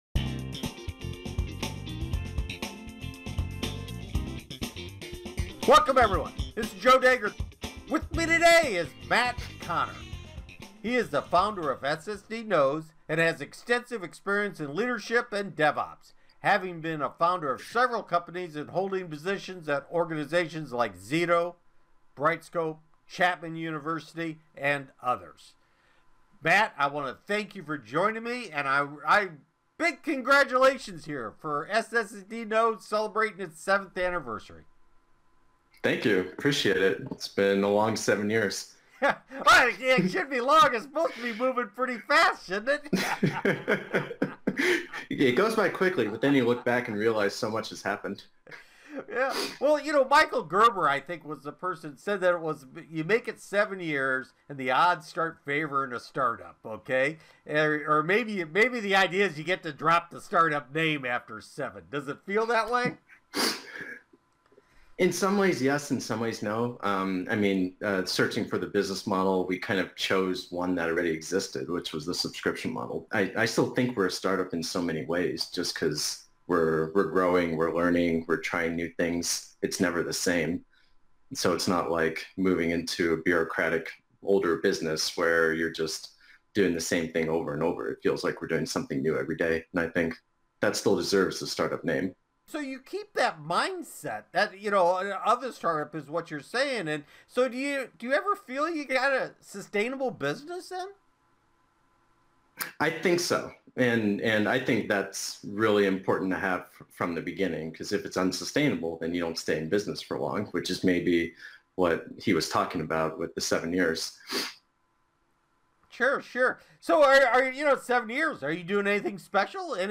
For the audio inclined, we have a recording of the full interview just below, or if text is more your thing, jump down for a full text transcript.